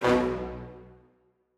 FX
[ACD] - GBE300 Hit.wav